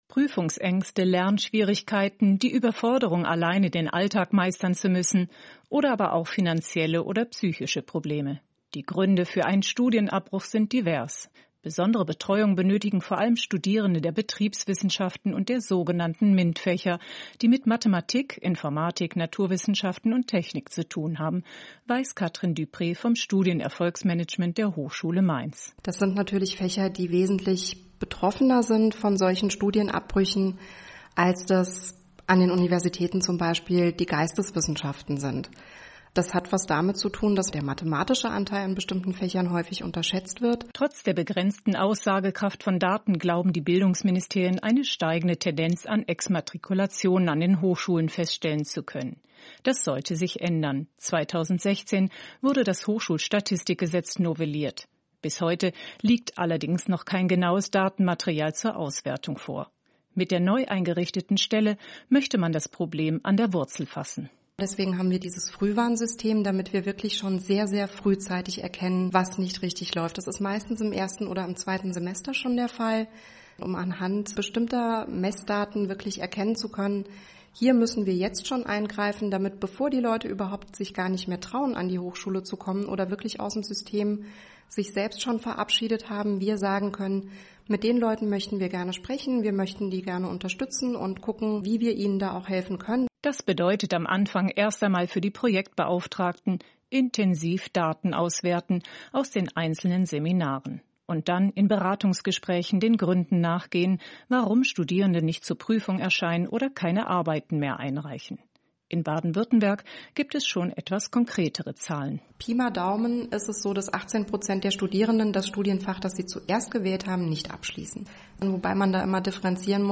SWR Interview